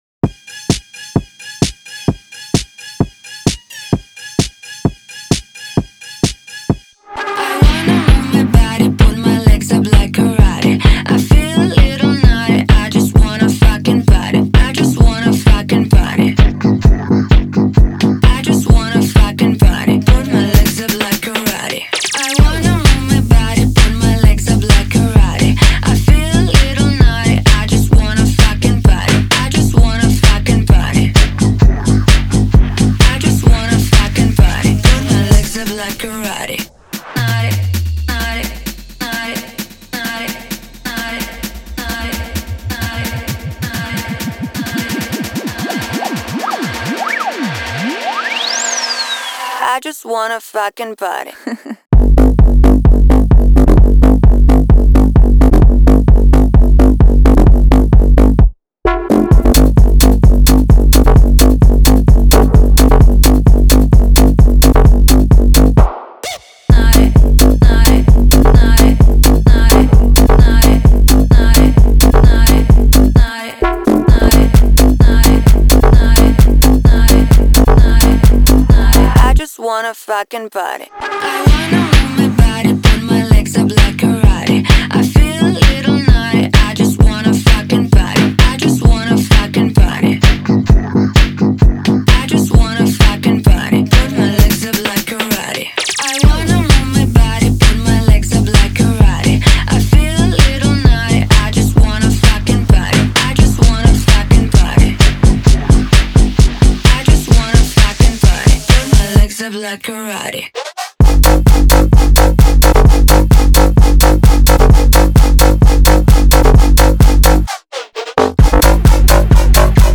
Звучание выделяется мощными басами и заводными ритмами